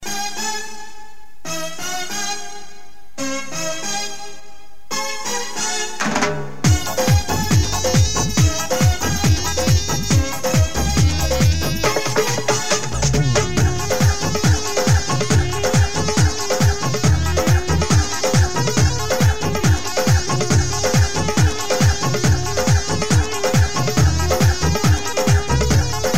Category: panjabi song